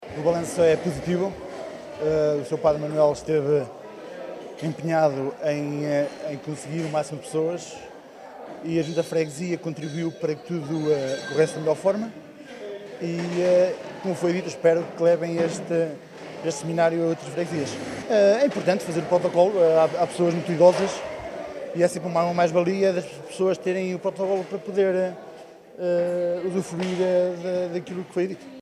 Declarações à margem da assinatura do protocolo de colaboração entre a ULS Nordeste e a UPSA, inserida no Seminário “Saúde – Do cuidar ao curar: a Fé como acto curativo” que aconteceu na manhã de sábado na aldeia de Arcas, Macedo de Cavaleiros.
Luís Rodrigues, presidente da Junta de Freguesia de Arcas, faz um balanço positivo da iniciativa, realçando a importância do protocolo que prevê a colaboração entre a saúde e a igreja.